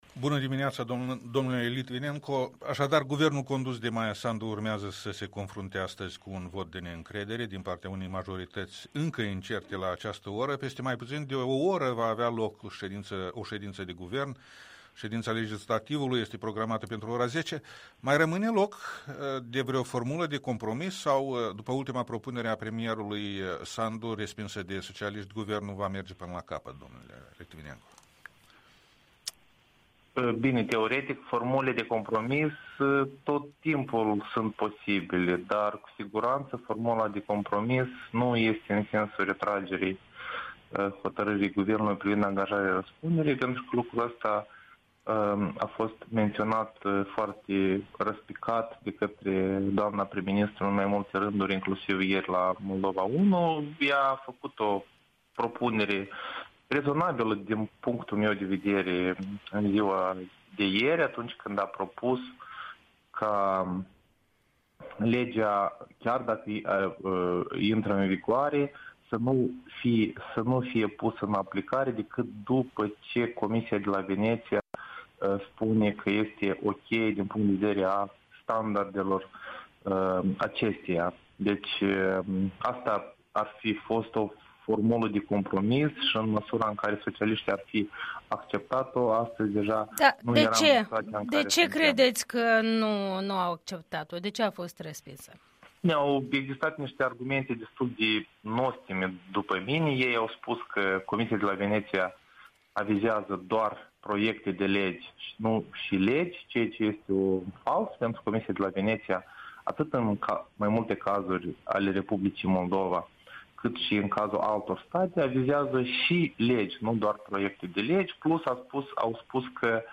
Interviul dimineții la EL: cu Sergiu Litvinenco